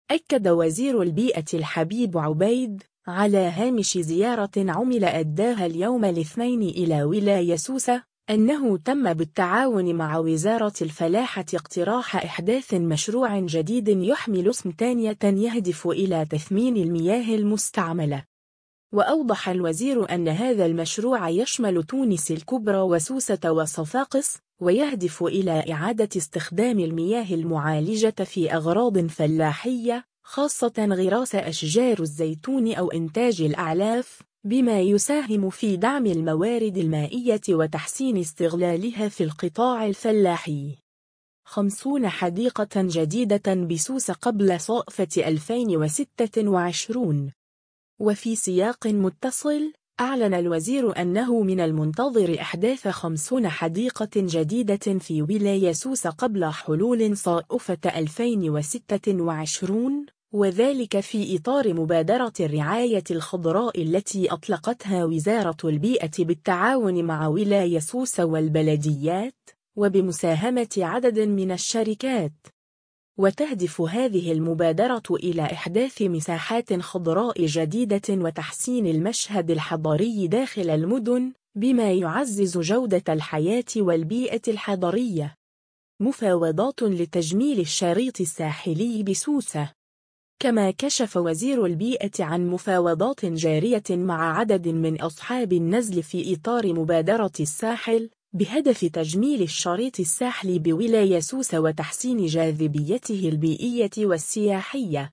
أكد وزير البيئة الحبيب عبيد، على هامش زيارة عمل أداها اليوم الاثنين إلى ولاية سوسة، أنه تم بالتعاون مع وزارة الفلاحة اقتراح إحداث مشروع جديد يحمل اسم «تانيت» يهدف إلى تثمين المياه المستعملة.